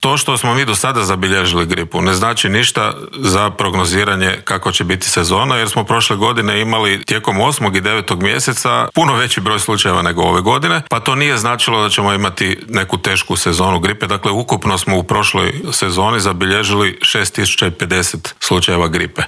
Možemo li se cijepiti protiv oba virusa u isto vrijeme, spremamo li se za tešku zimu po pitanju obje zarazne bolesti, kao i hoćemo li za dvije godine imati Imunološki zavod, pitanja su na koja nam je, između ostalih, odgovor dao u Intervjuu tjedna Media servisa ravnatelj Hrvatskog zavoda za javno zdravstvo Krunoslav Capak.